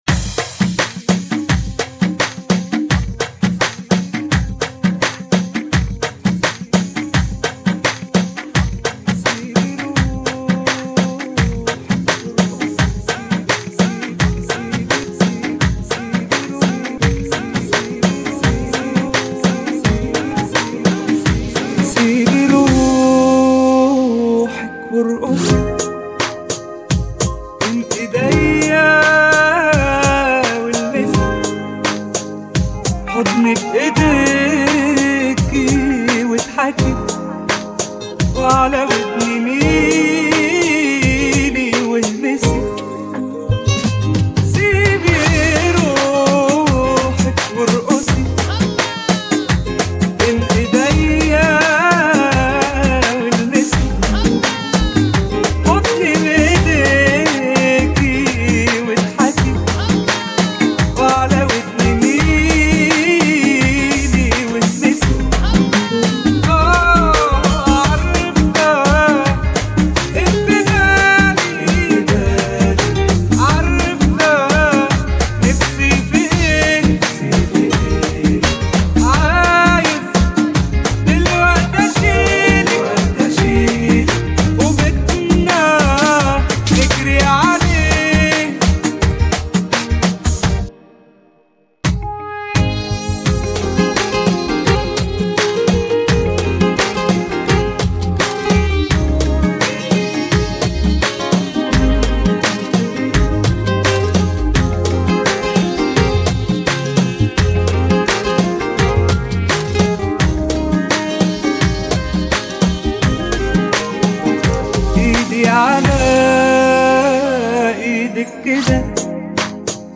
[ 85 bpm ] EDIT 2023